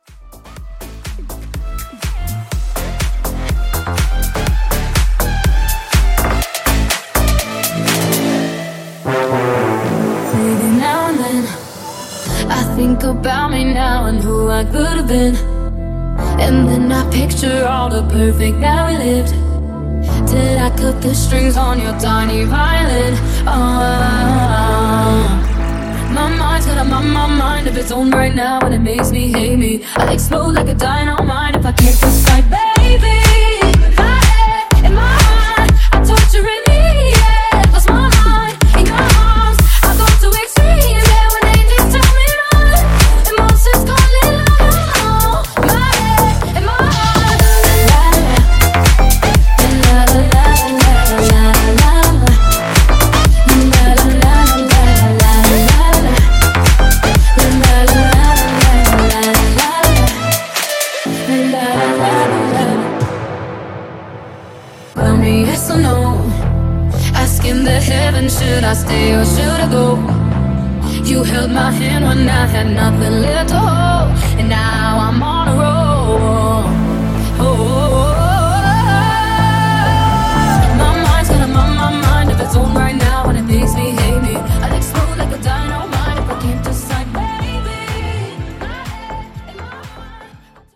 FUTURE HOUSE , MASHUPS , TOP40 Version: Clean BPM: 123 Time